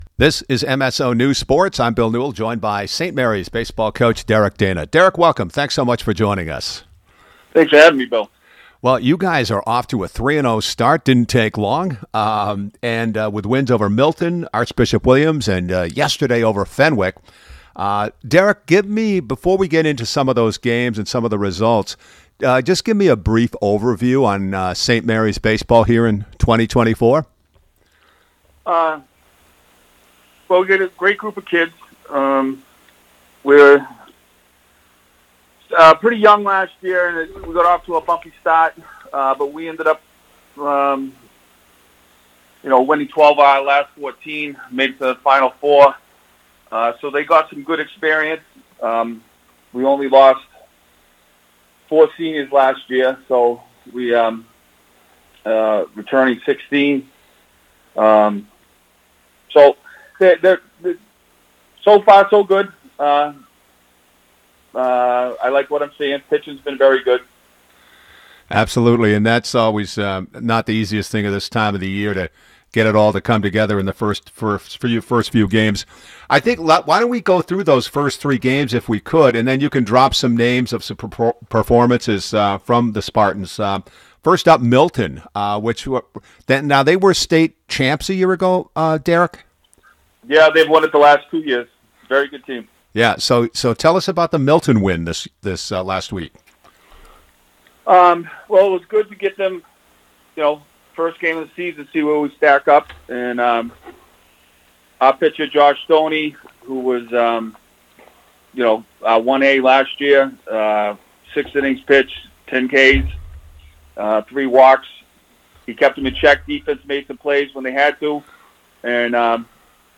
In this podcast interview